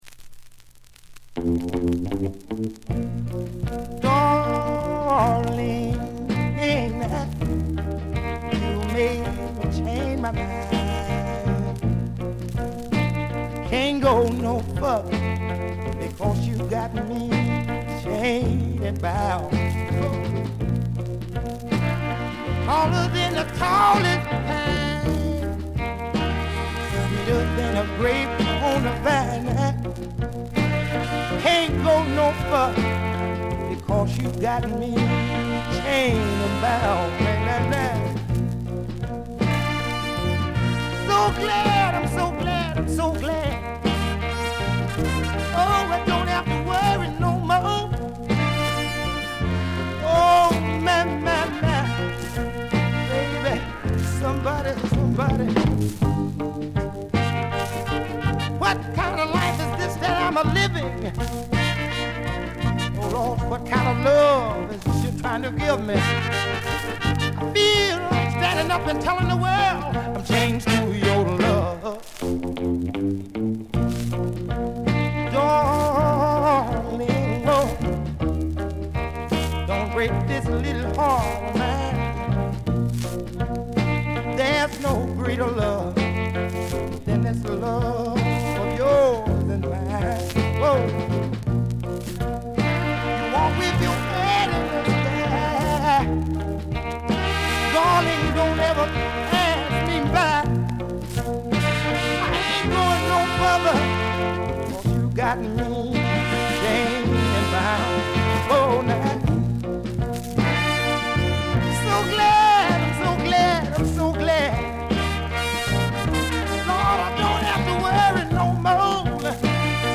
全編通じて強めのバックグラウンドノイズが出ます。
音質はよくありませんがコレクターの方はお見逃しなく。
試聴曲は現品からの取り込み音源です。
vocals